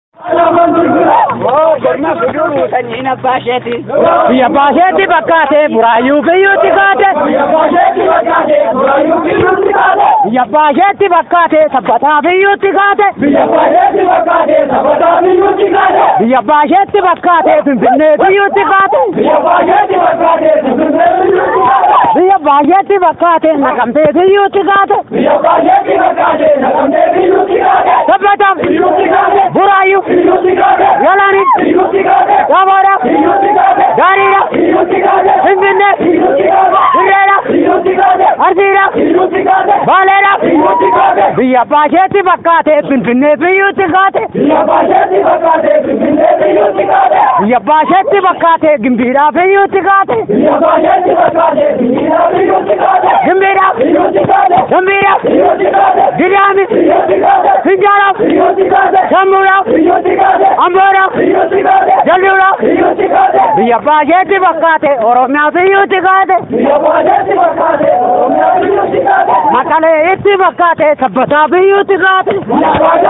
Goototni Dargaggootni fi Barattootni Oromoo Walleelee Warraaqsaan ABO,Qabsoo Bilisummaa Oromoo fi gootota ilmaan Oromoo
jechuun sagalee dhaadannoo jajjabaa fi dheekkamsaan guutame dhageesisan.
kabachiisa, jechuun labsii dhaadannoo fi walleelee warraaqsaan guutame dabarfatan.